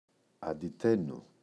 αντιταίνω [andi’teno]